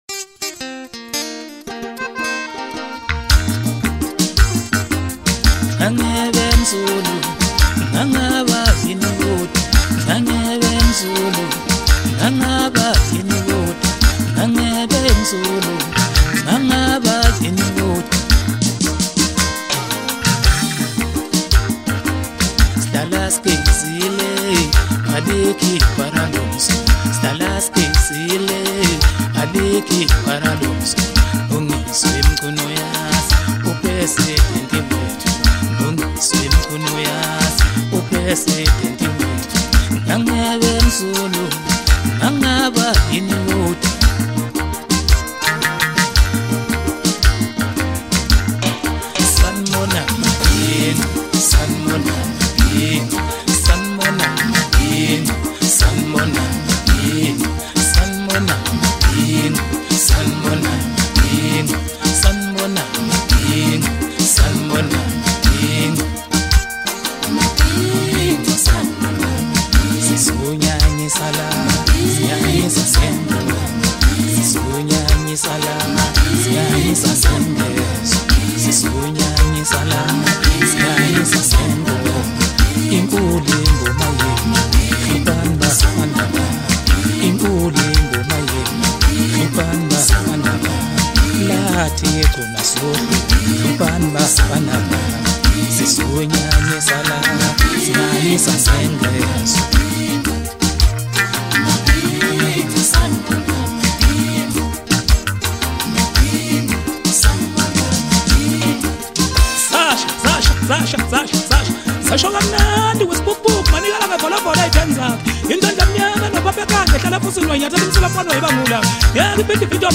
Home » Hip Hop » Maskandi
smooth rhythm, catchy flow